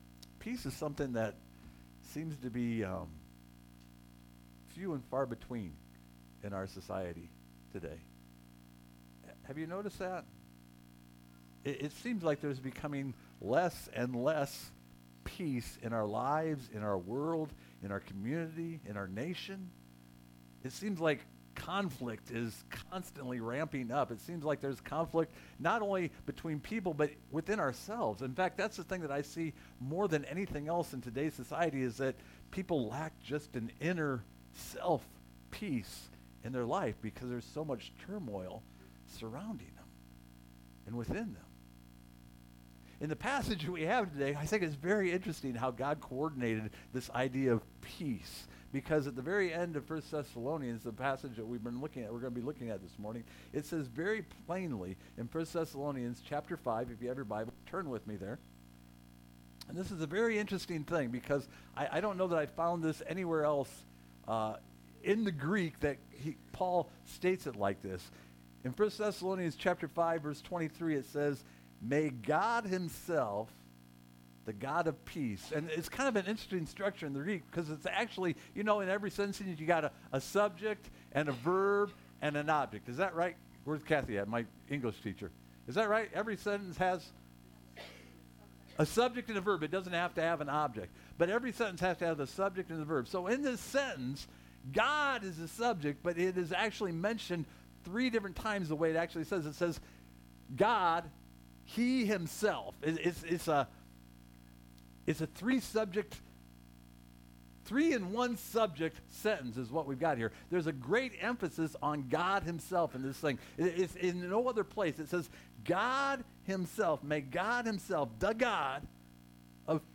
Service Type: Sunday Morning Topics: glorification , justification , Peace , relationships , sanctification « Write Your Story with Hope